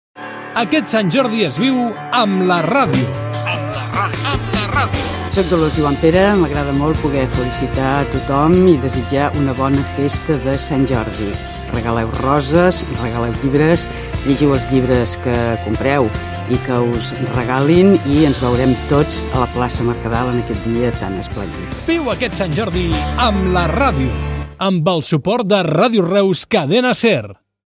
Felicitació per Sant Jordi - Ràdio Reus, 2005